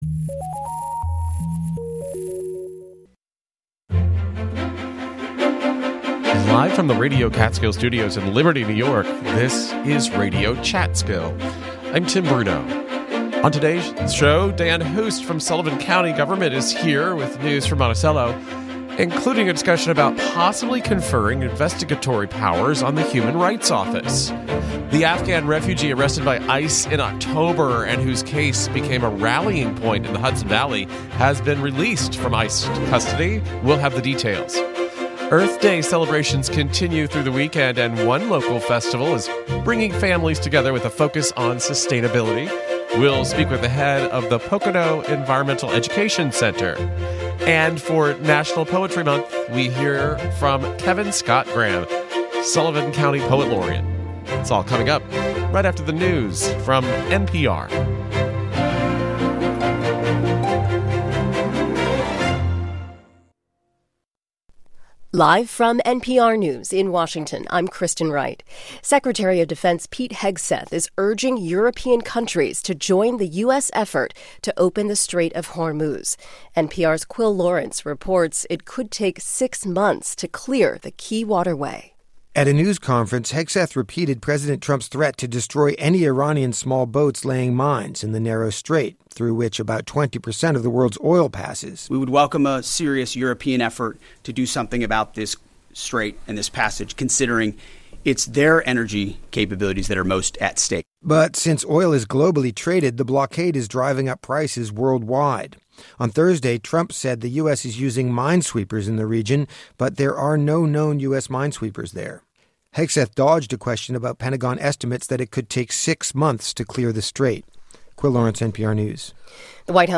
Live, local conversations focused on arts, history, and current news.